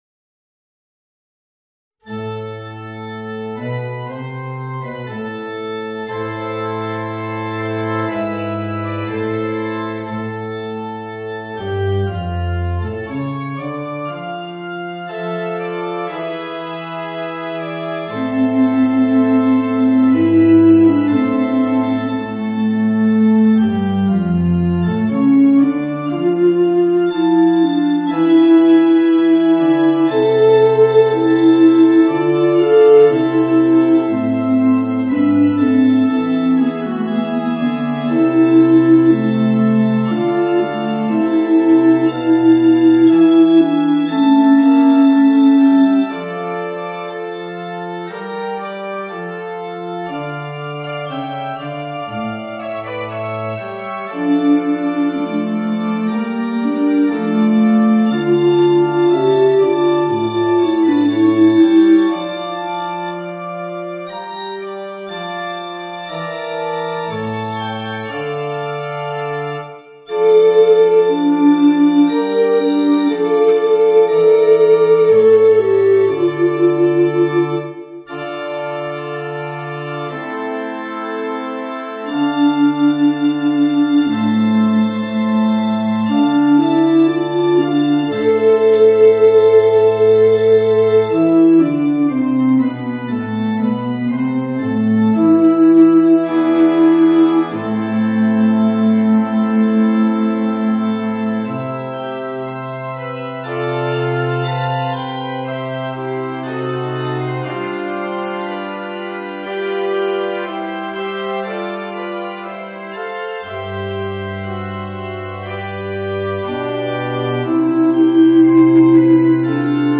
Voicing: Bass Recorder and Organ